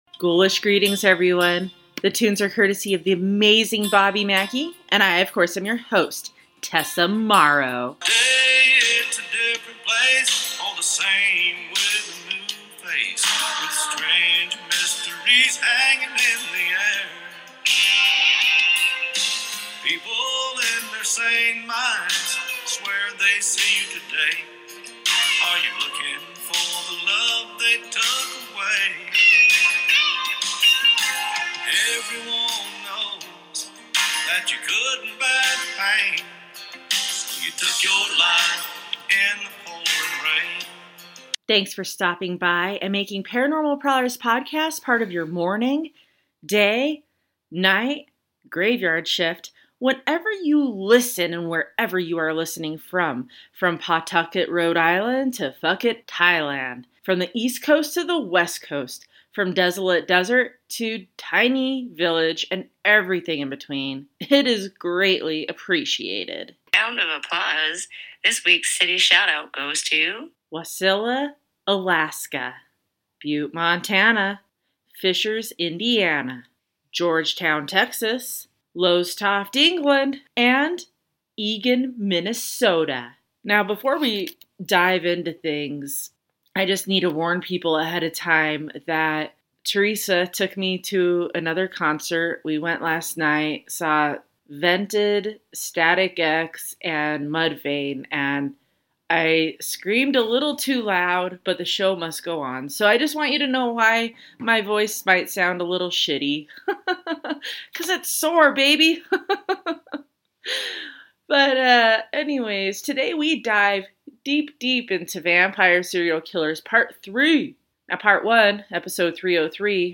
VOICE OVER SHOUT OUTS: